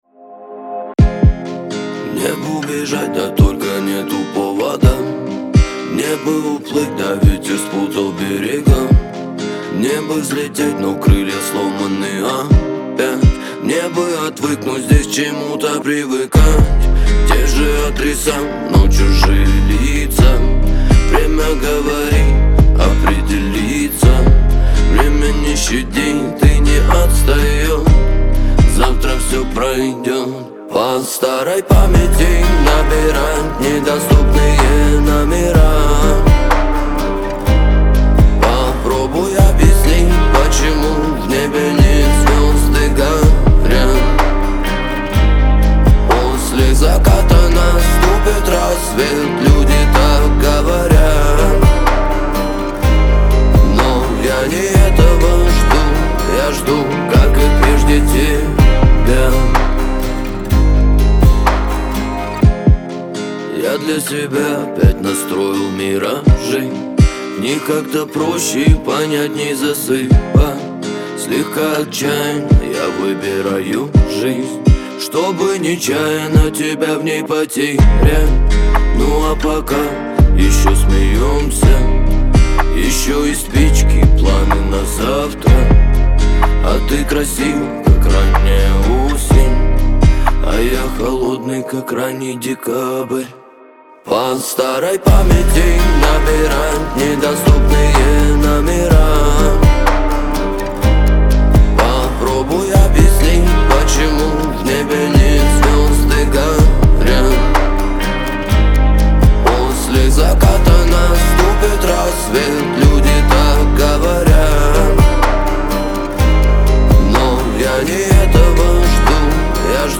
эстрада , диско
pop